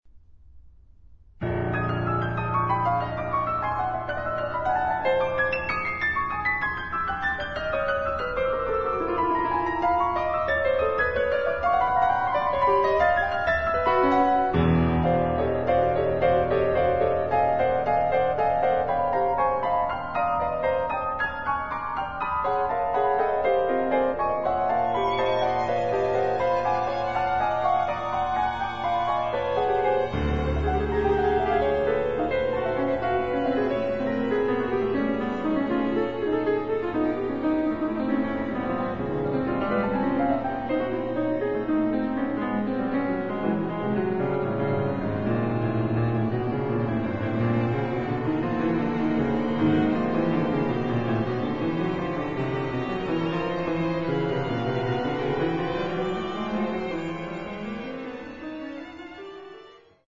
Cello
Piano
Viola
Violin
St Silas, Chalk Farm, London